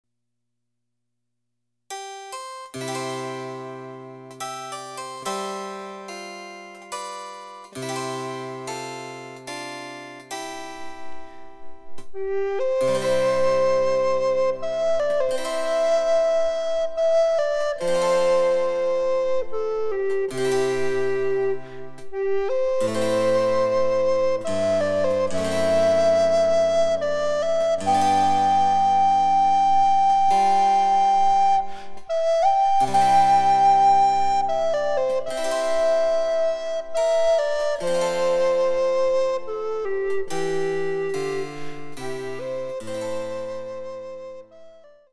あの名曲をＣＤのチェンバロ伴奏で演奏して楽しもう！
★イギリスに由来する名歌４曲をアルトリコーダーで演奏できる「チェンバロ伴奏ＣＤブック」です。
・各曲につき、テンポの異なる３種類のチェンバロ伴奏
デジタルサンプリング音源使用